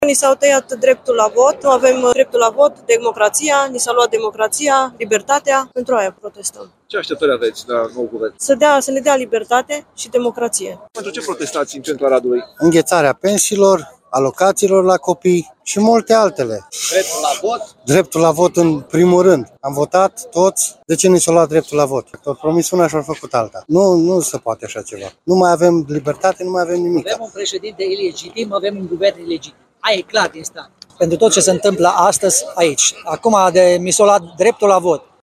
Protest în fața primăriei din Arad
vox-arad.mp3